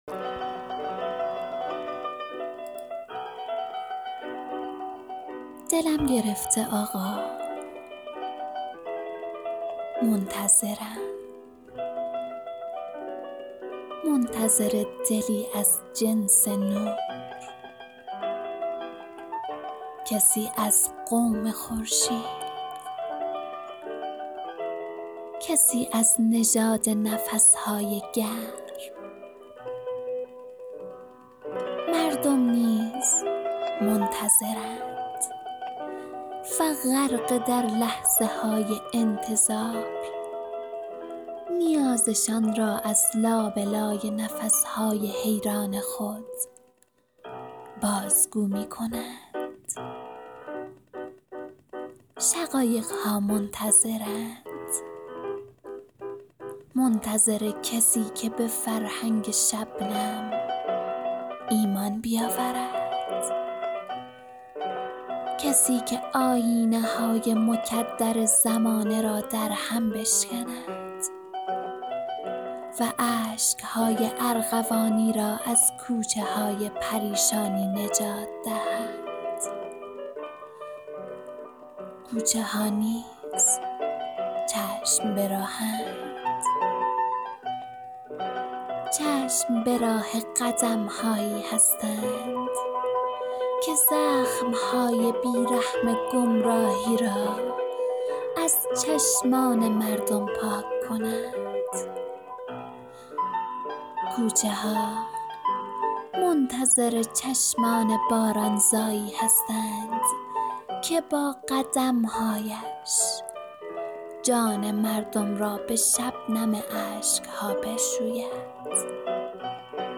دکلمه ای باصدای خودم.